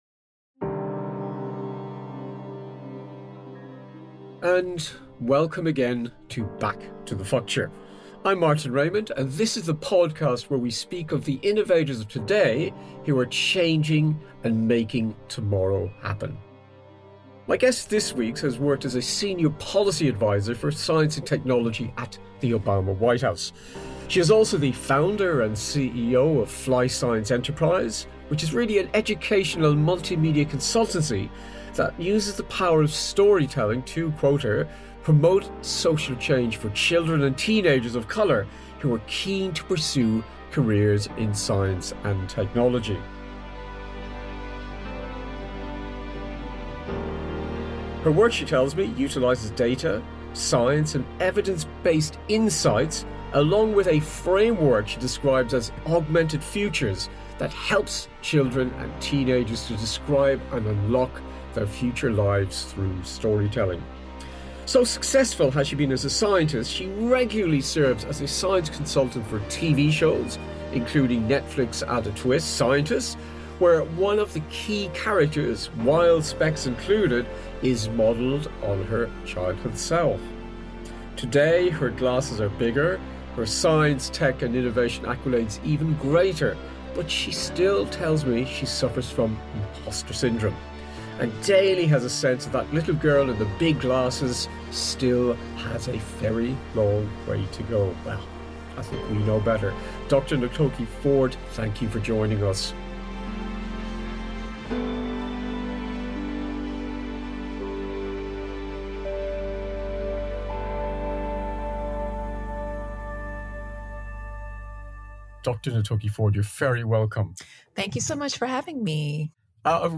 In this conversation, we delve into our shared experience of imposter syndrome, the case for adding the A of ‘arts’ into STEM (Science, Technology, Engineering, Maths), the year 2034 and the power of high vibrations.